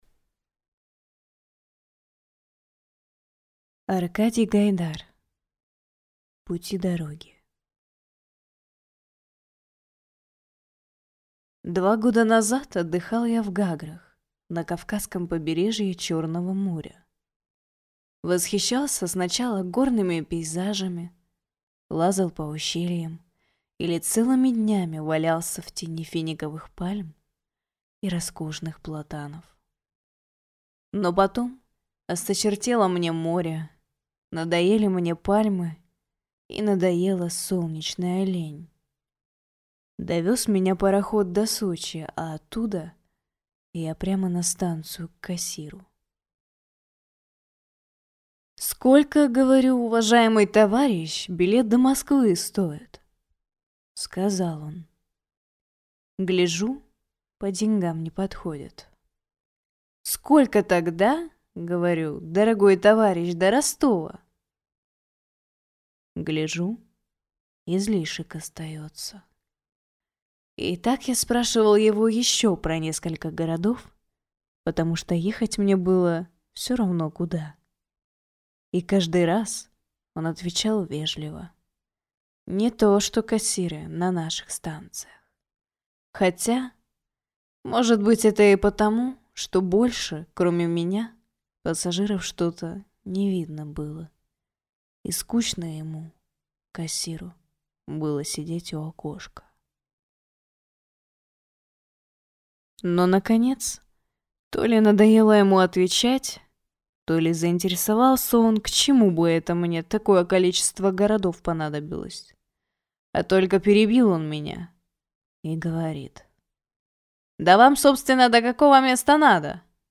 Аудиокнига Пути-дороги | Библиотека аудиокниг